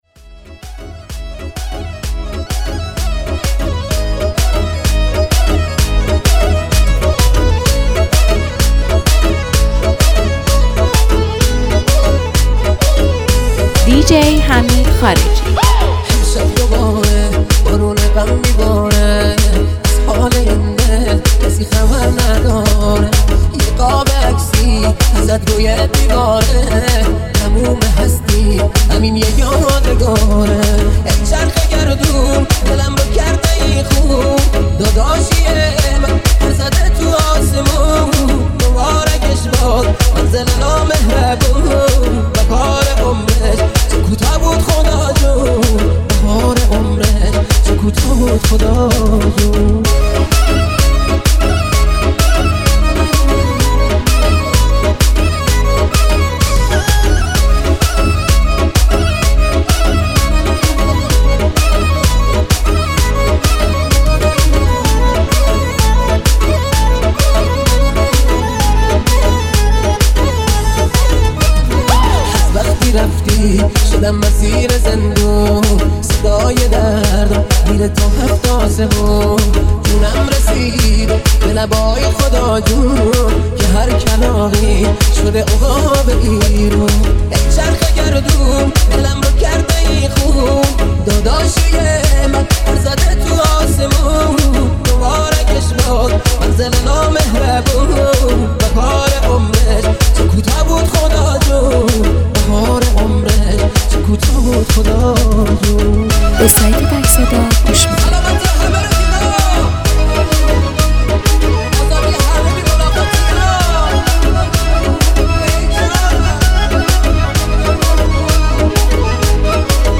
{بیس دار}